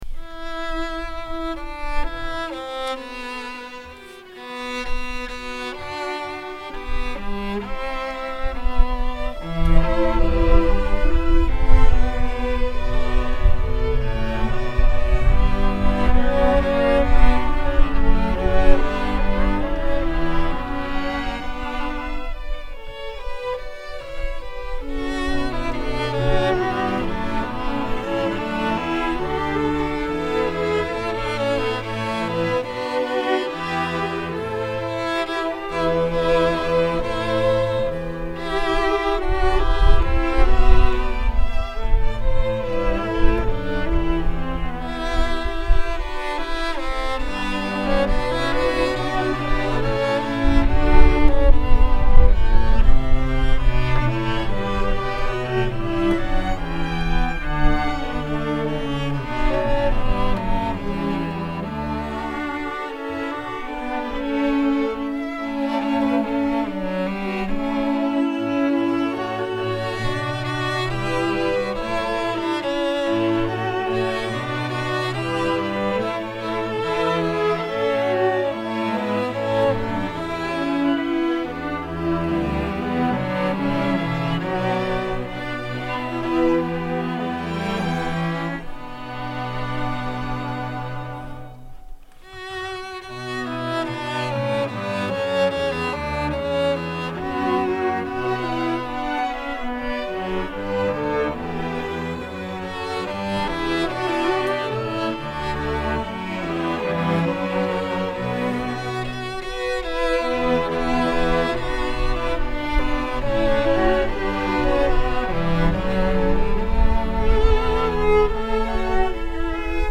“Veni Domine” για Ορχήστρα Εγχόρδων (live)